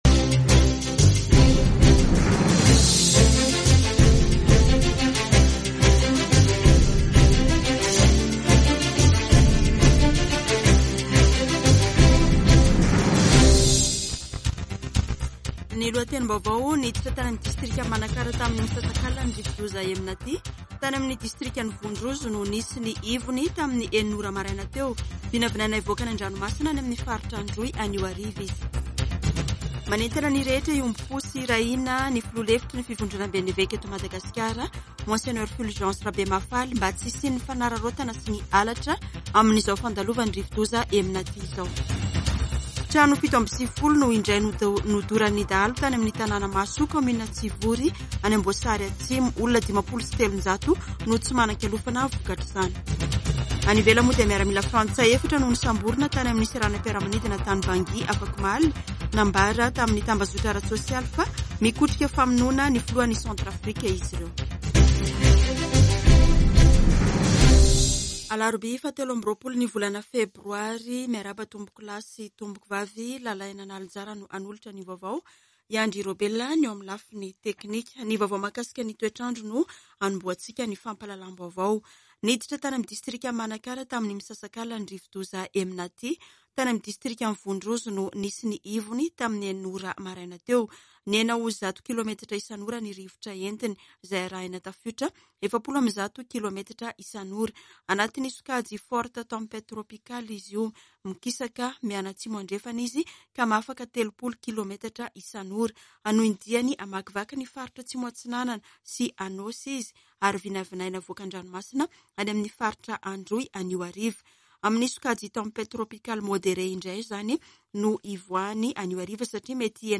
[Vaovao maraina] Alarobia 23 febroary 2022